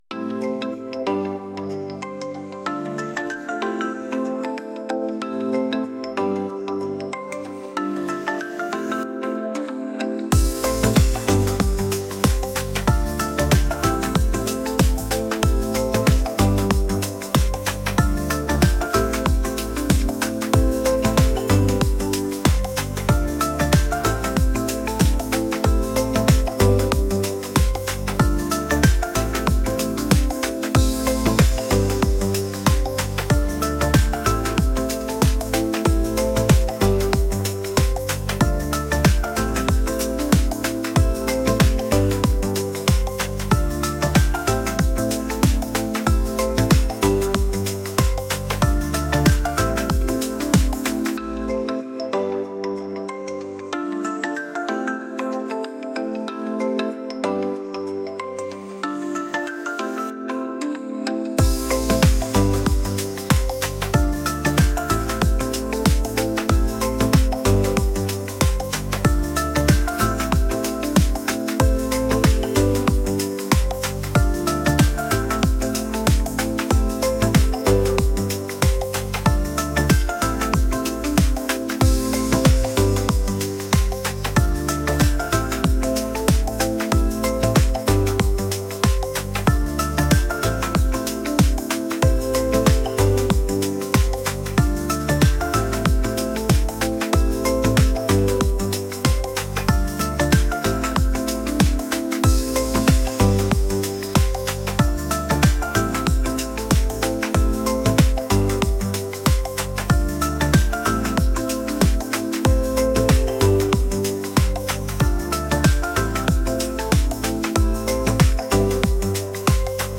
relaxed | pop